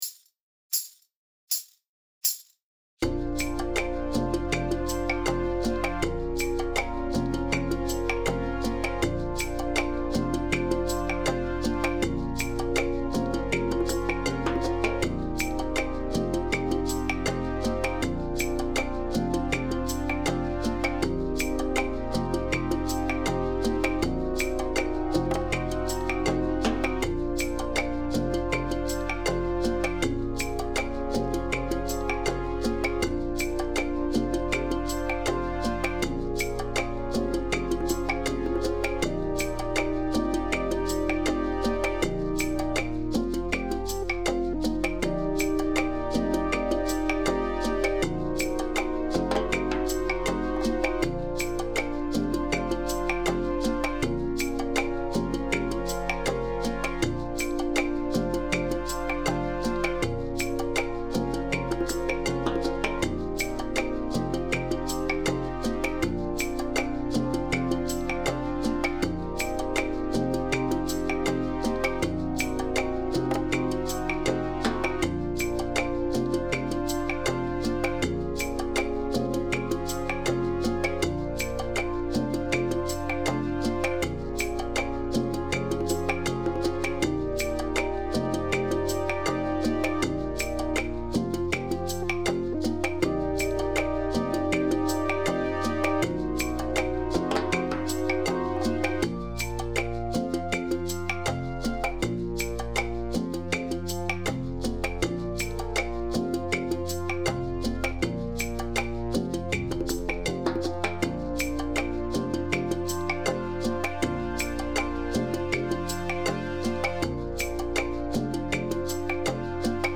A relaxed melody with a Rumba beat.
Andante